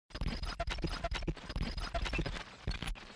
noise.mp3